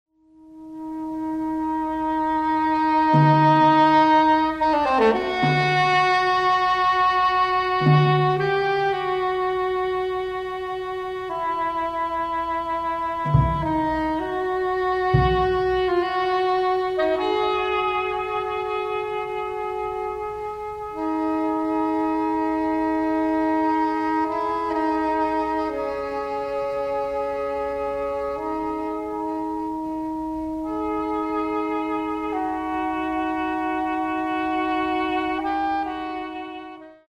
for Two Saxophonists and Three Percussionists
Saxophone and Percussion